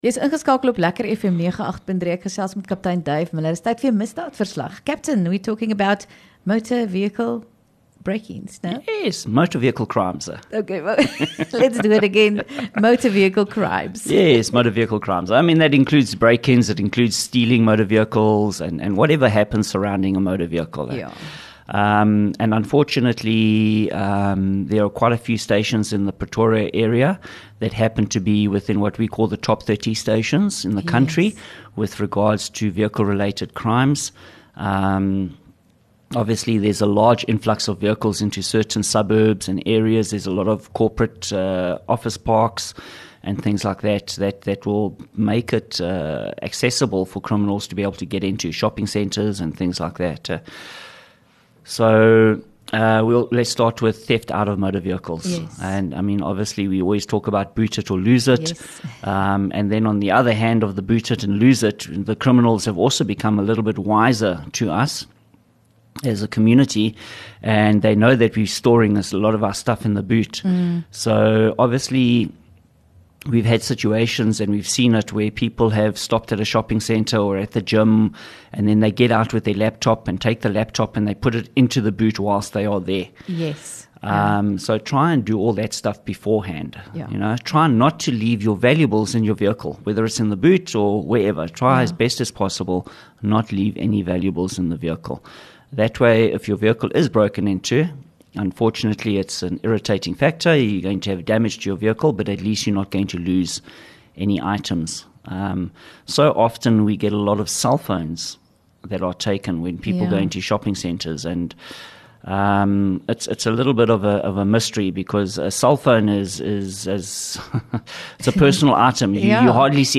LEKKER FM | Onderhoude 15 Oct Misdaad Verslag